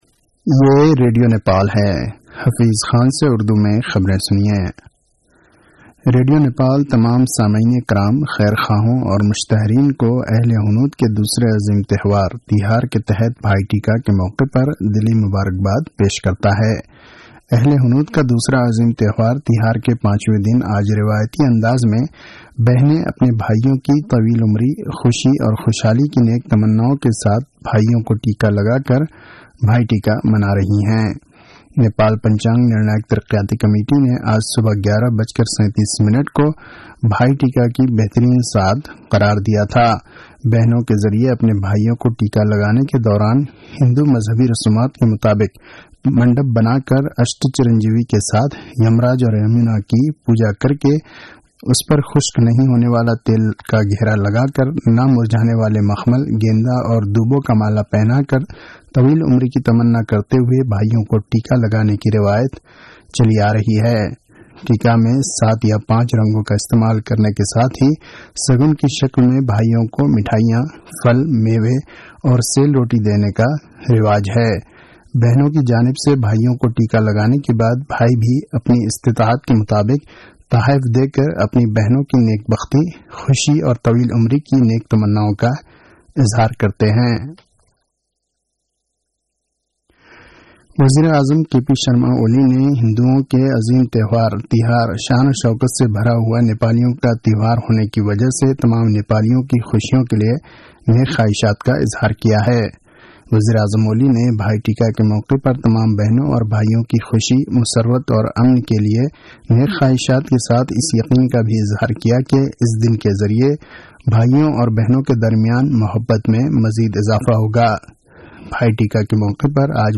An online outlet of Nepal's national radio broadcaster
उर्दु भाषामा समाचार : १९ कार्तिक , २०८१